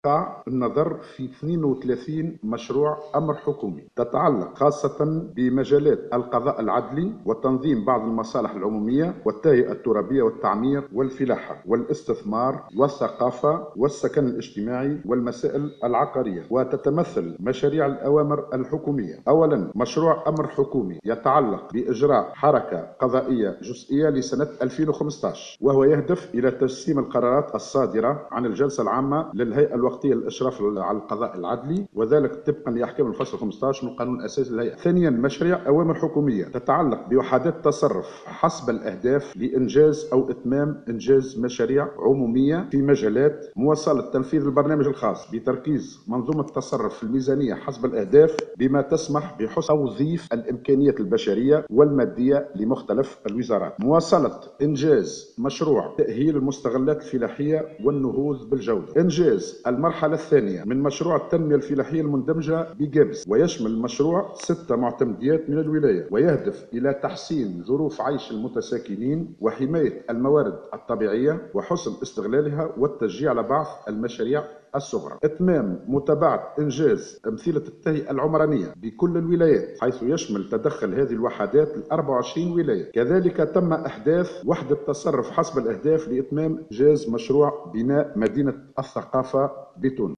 وبحسب ما أوضحه وزير املاك الدولة والشؤون العقارية حاتم العشي في ندوة صحفية بالقصبة عقدها على اثر هذا الاجتماع، تتمثل أبرز مشاريع هذه الأوامر الحكومية في مشروع أمر حكومي يتعلق بإجراء حركة قضائية جزئية لسنة 2015.